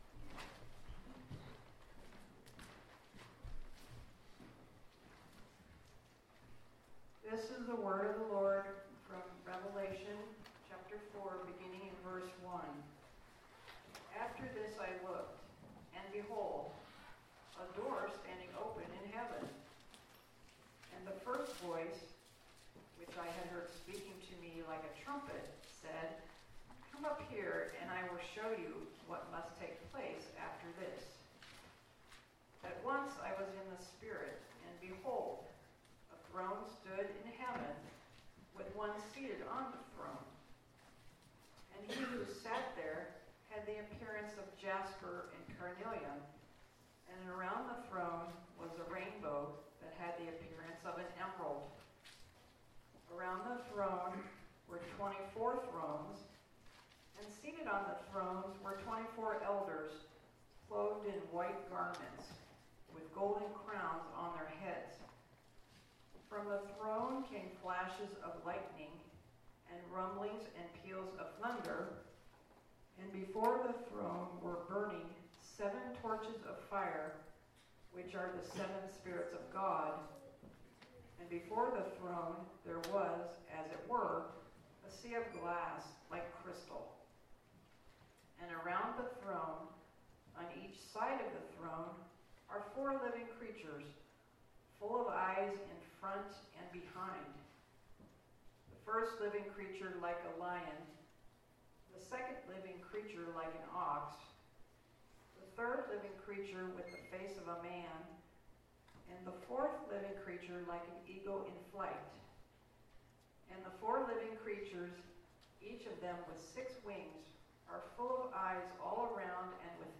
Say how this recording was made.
Passage: Revelation 4 and 5 Service Type: Sunday Morning